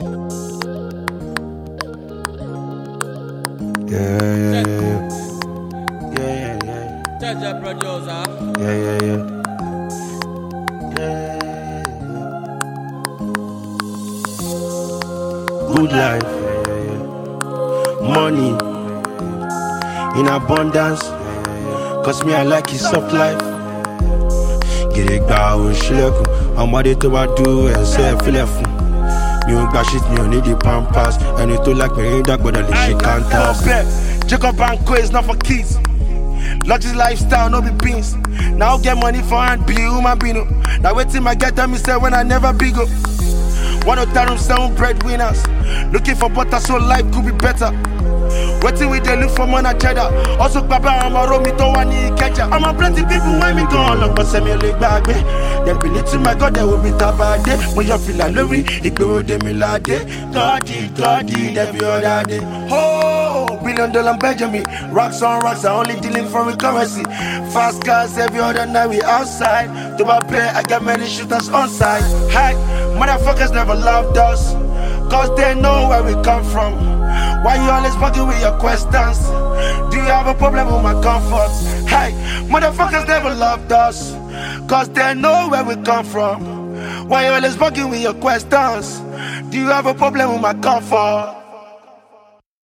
rap freestyle